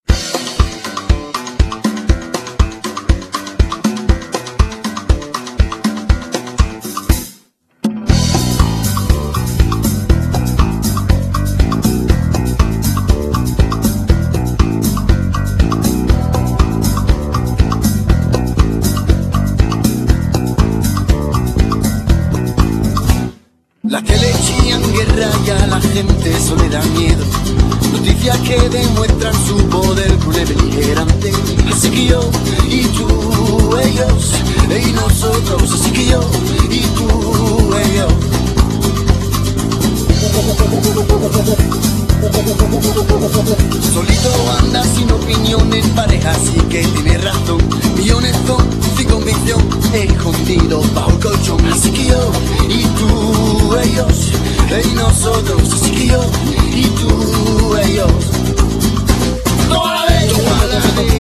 Genere : Pop latino